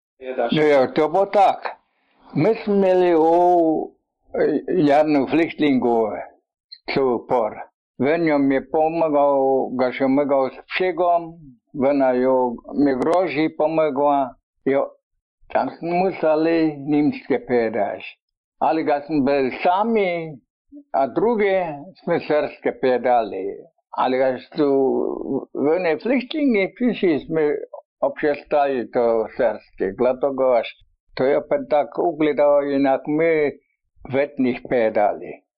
Yet it sounds Slavic, perhaps Polish, although rather bad drunken slurred Polish with strong German accent, which makes it surprisingly unintelligible for a Slavic language.